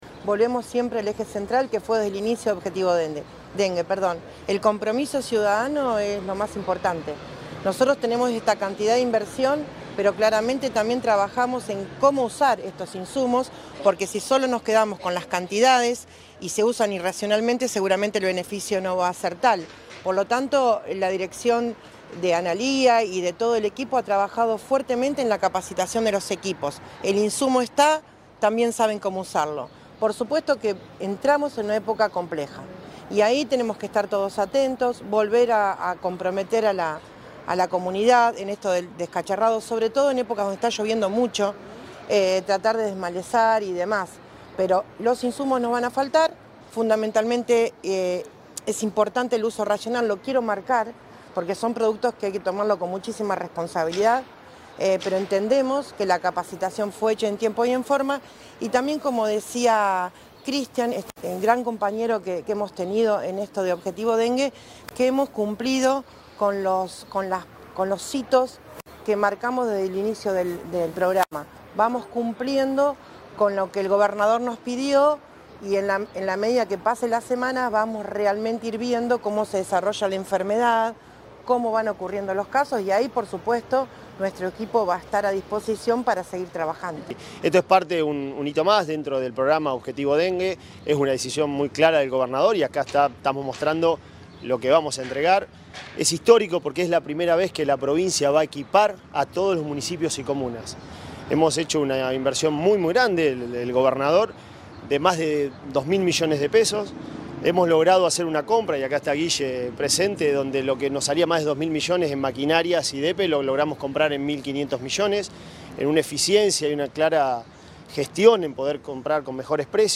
La explanada del Centro de Especialidades Médicas de Santa Fe (Cemafe), en la ciudad capital, fue escenario este martes de la presentación de insumos y maquinaria que adquirió el Gobierno de la Provincia en el marco del programa de prevención Objetivo Dengue.
La actividad estuvo encabezada por la ministra de Salud, Silvia Ciancio; acompañada por el secretario de Cooperación de Gobierno, Cristian Cunha; la directora de Prevención y Promoción, Analía Chumpitaz; y el secretario de Administración del Ministerio de Salud, Guillermo Álvarez.
Declaraciones de Ciancio y Cunha